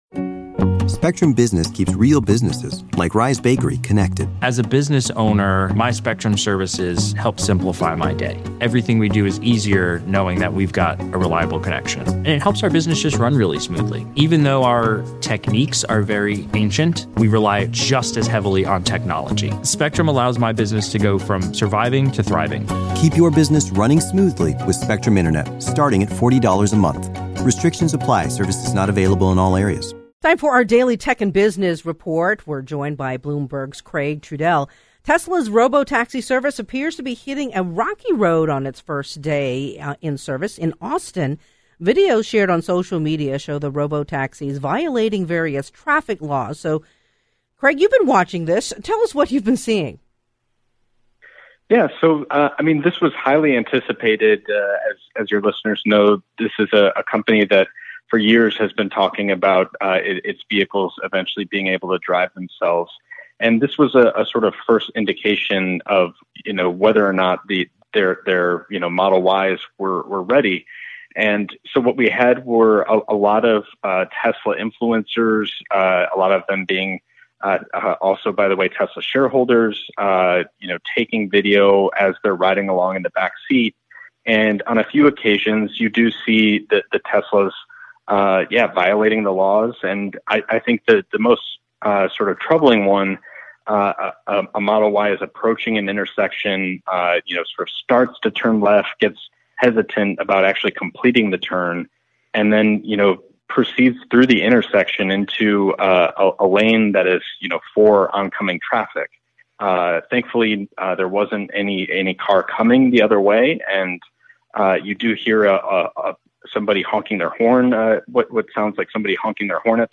Time now for our daily Tech and Business Report.